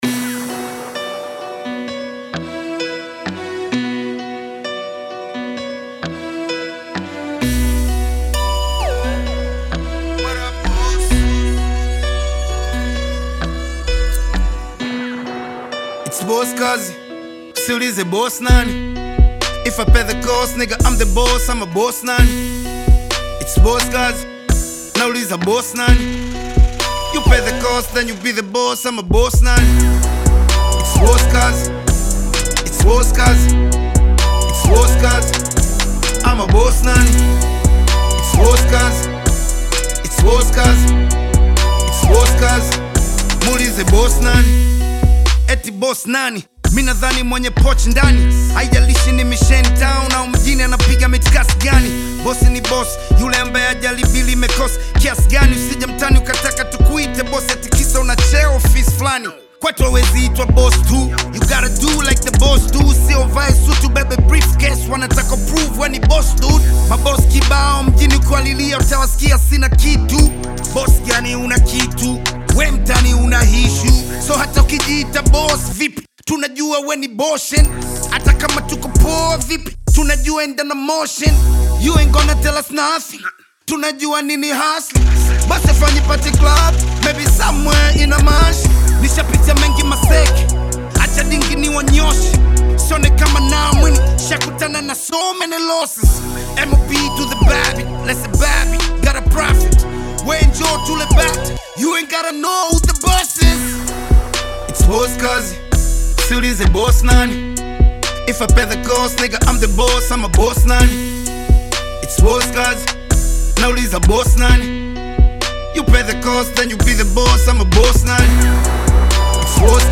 energetic beats and powerful lyrics